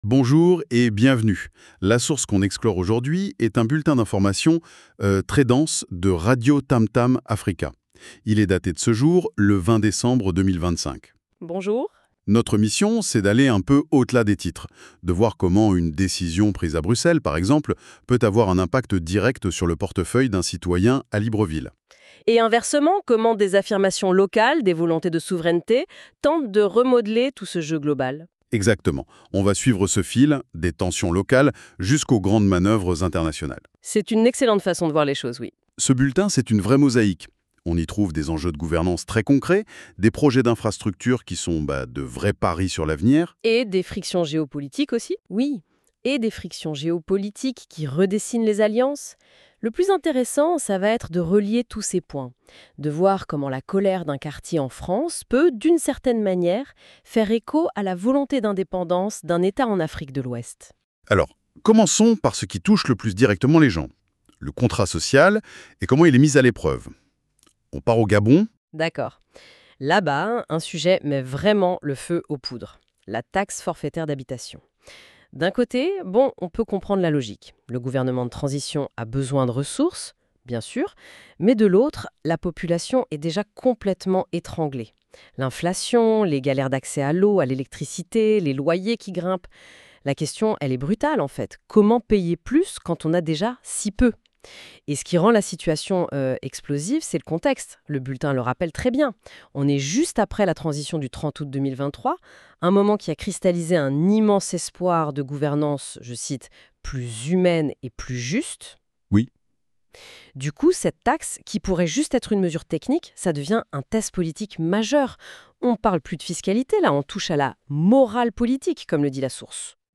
Radio TAMTAM AFRICA BULLETIN D’INFORMATION – RADIOTAMTAM AFRICA BULLETIN D’INFORMATION 20 décembre 2025